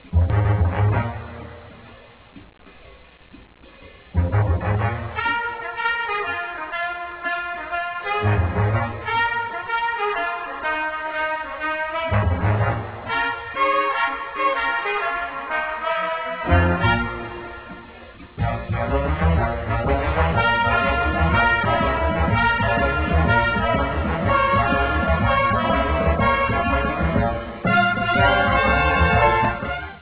colonna sonora a tutto jazz
Track Music